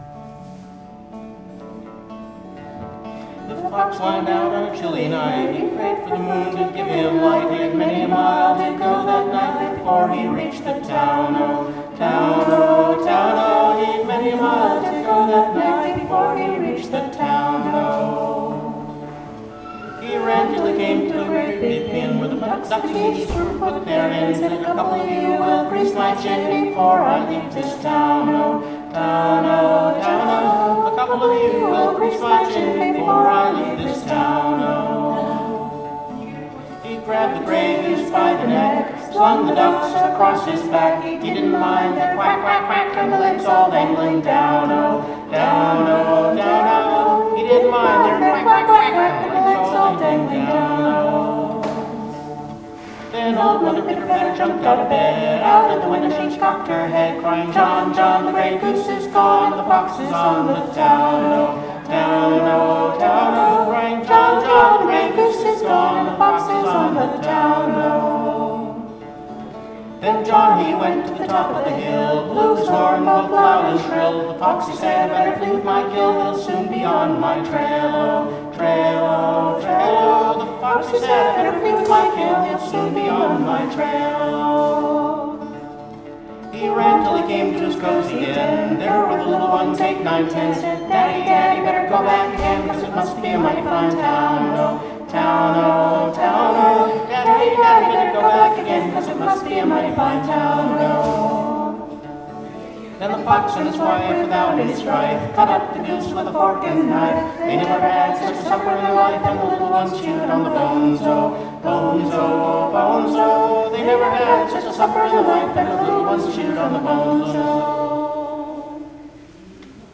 This was recorded under slightly adverse conditions: in the cafeteria of the nursing home, with somebody loudly chopping vegetables about 10 feet away.